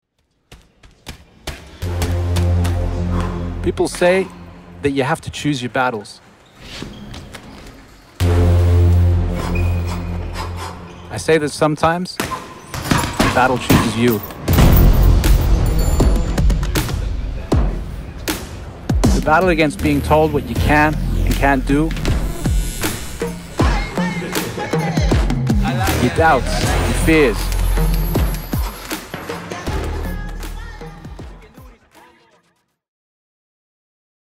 strong, courageous, feisty